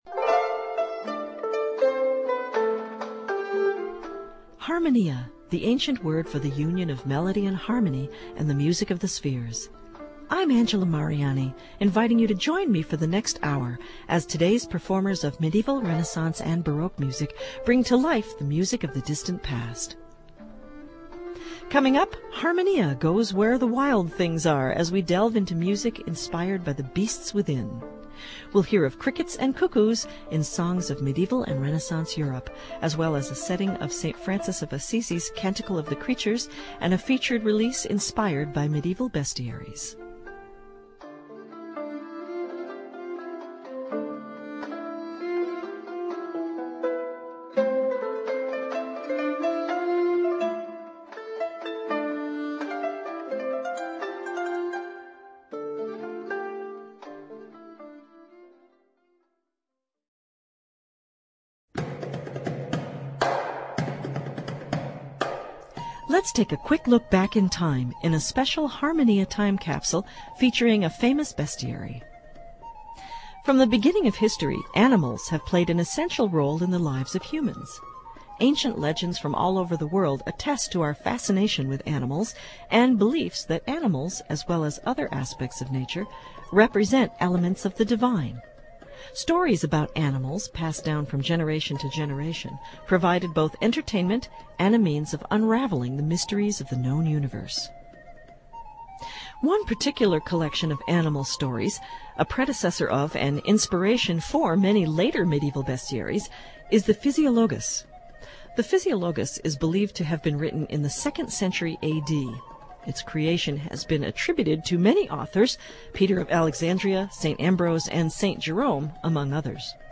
Harmonia goes where the wild things are as we delve into music inspired by the beasts within.